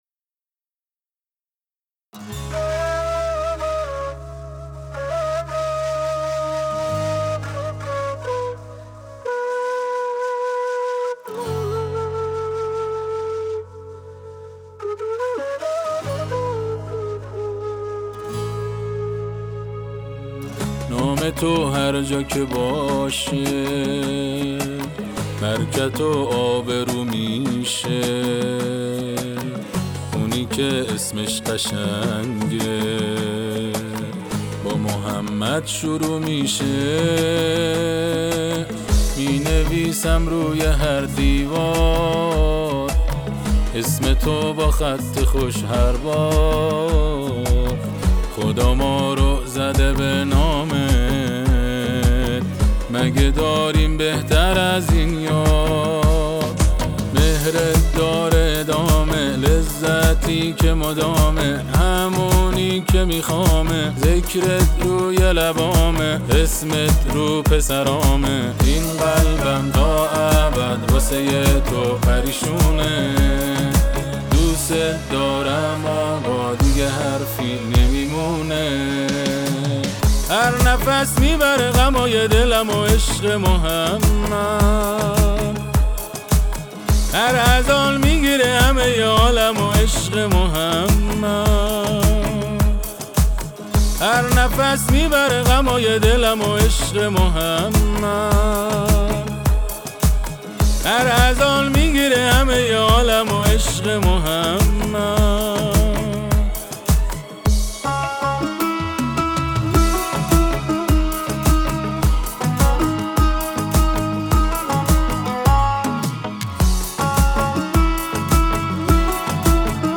اثر آوایی
به مناسبت سالروز ولادت پیامبر اکرم(ص)